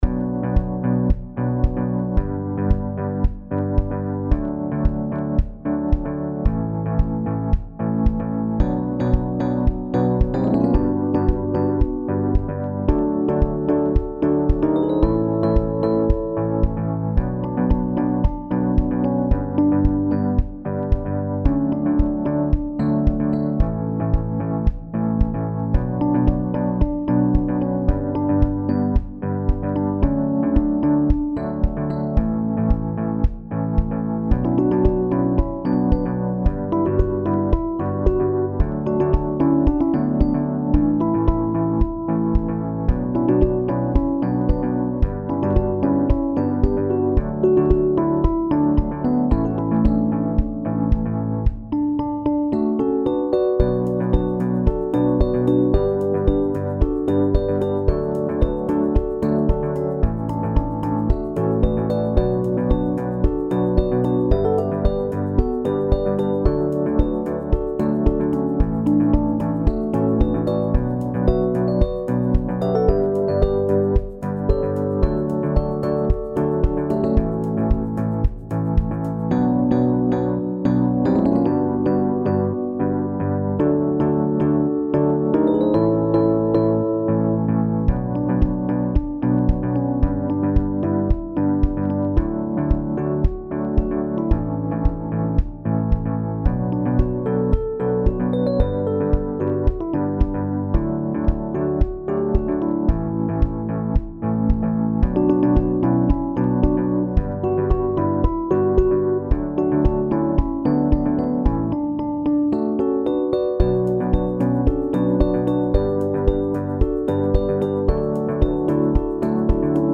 SSAA met piano